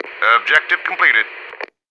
marine_order_complete3.wav